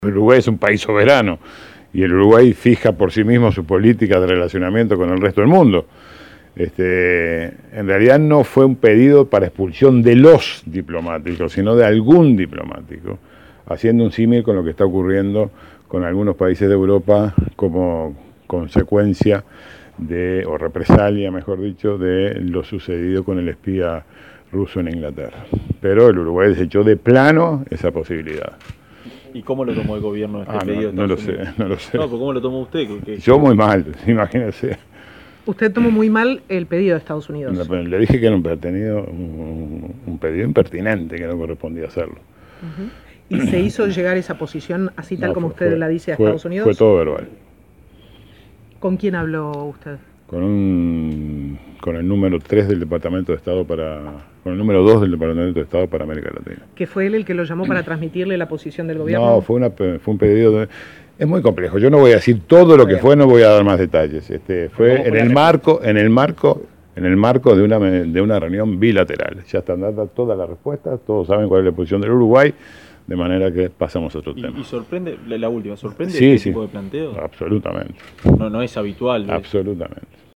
Ante la consulta de la prensa respecto a un diálogo entre representantes del gobierno de Estados Unidos que solicitaron el retiro de diplomáticos rusos, el canciller Rodolfo Nin Novoa respondió que Uruguay es un país soberano que fija por sí la forma de relacionamiento con el mundo. Se mostró sorprendido por el planteo, que no es habitual en el mundo diplomático.